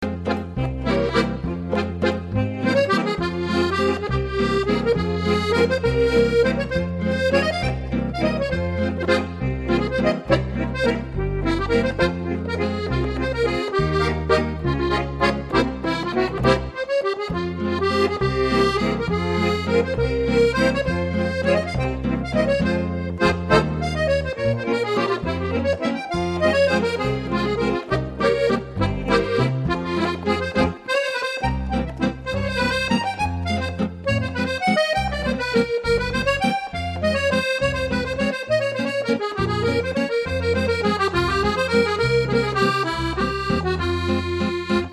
Accordion Players Continental Music - Italian, French, German, Greek,
Accordion 1.mp3